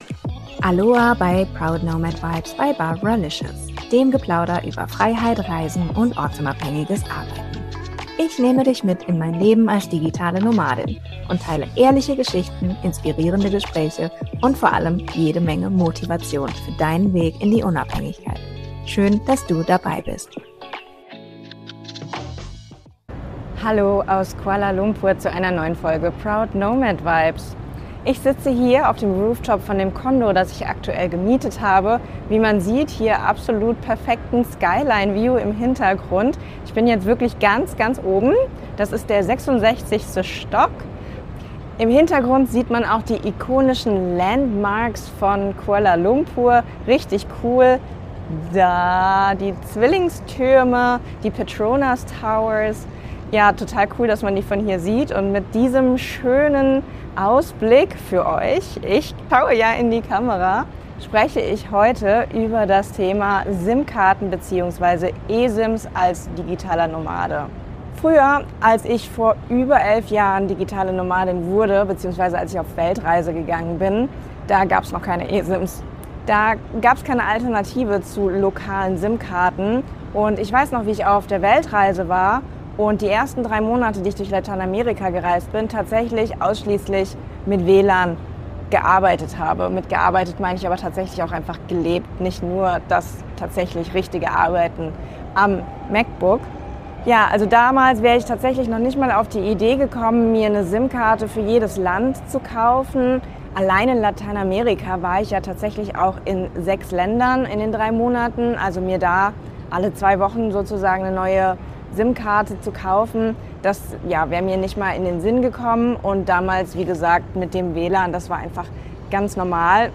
Ich nehme diese Episode von einem Rooftop in Kuala Lumpur auf und teile meine Erfahrungen aus über elf Jahren Reisen – von Zeiten ohne eSIMs bis hin zu heutigen glob...